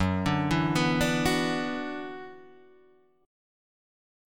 Gb7sus4#5 chord